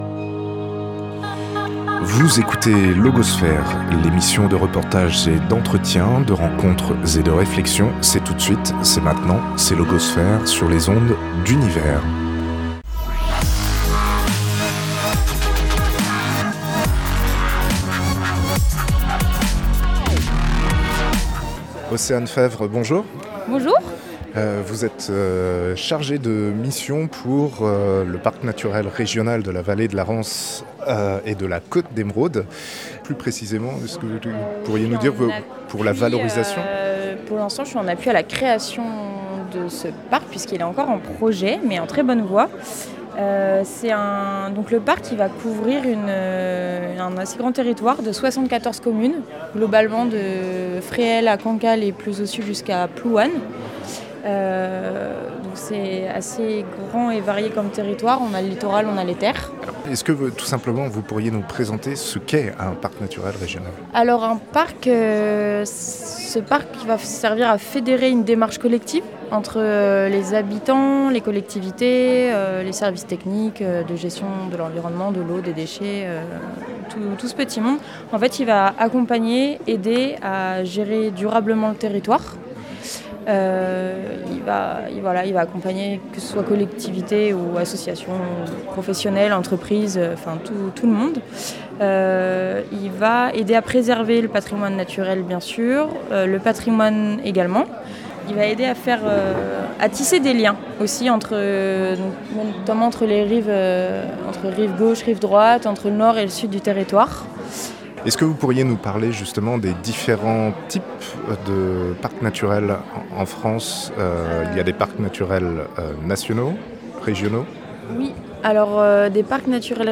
Entretien en date de septembre 2023